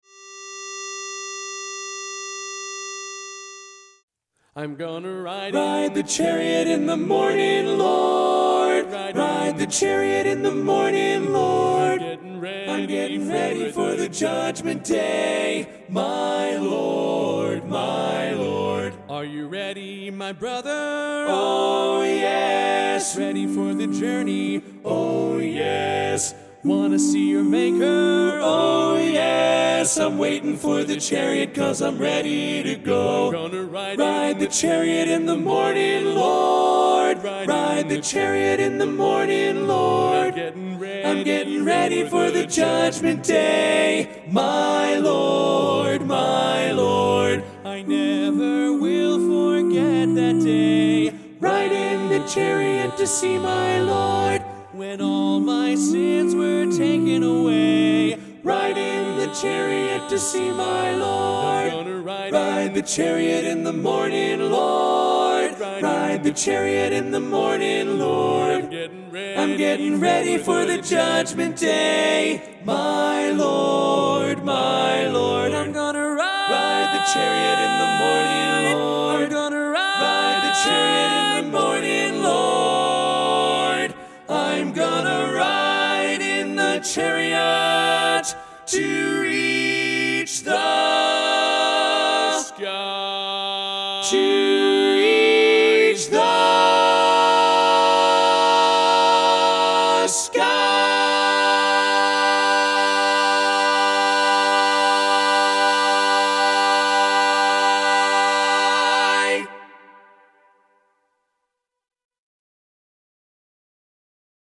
Kanawha Kordsmen (chorus)
Up-tempo
F Major
Lead